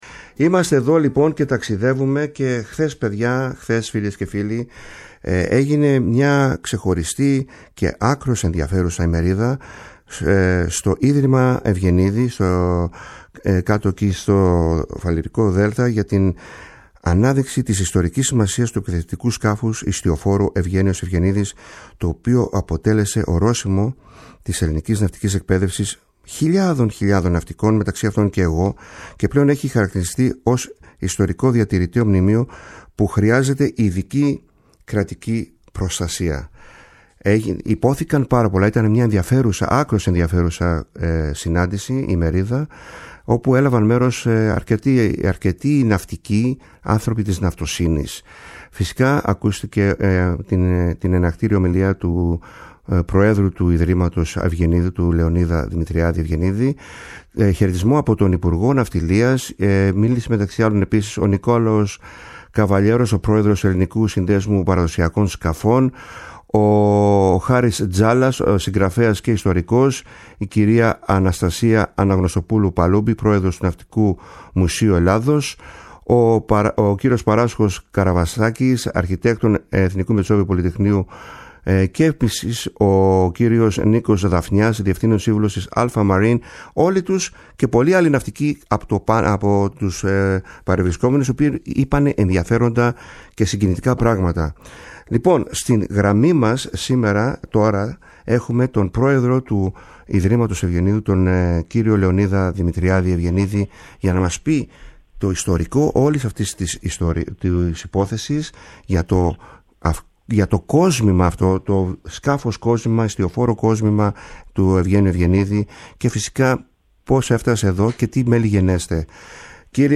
Η ΦΩΝΗ ΤΗΣ ΕΛΛΑΔΑΣ Καλες Θαλασσες ΣΥΝΕΝΤΕΥΞΕΙΣ Συνεντεύξεις